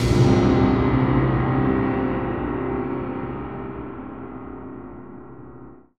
Synth Impact 21.wav